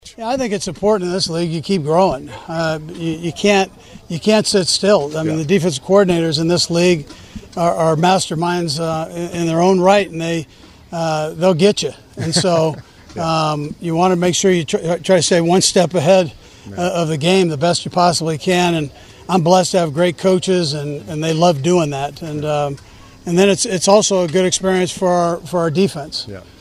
Head Coach Andy Reid says even though they have played in the last 2 Super Bowls they need to keep evolving.